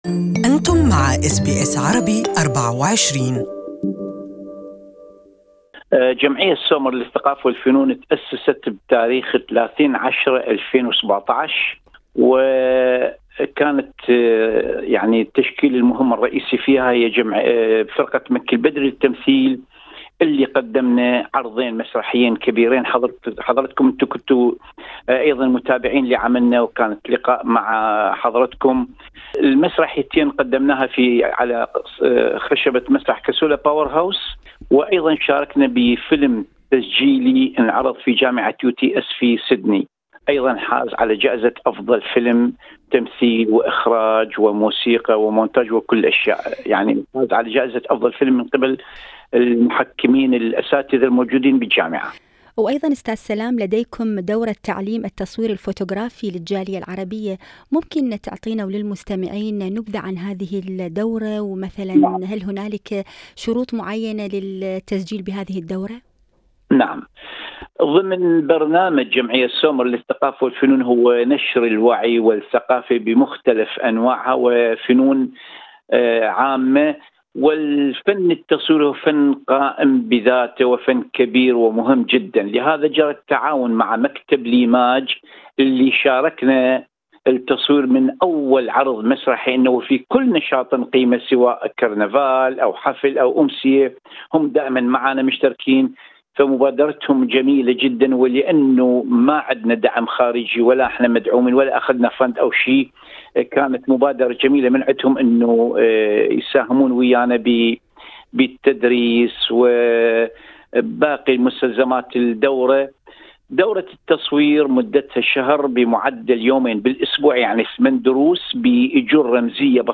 This interview is only available in Arabic.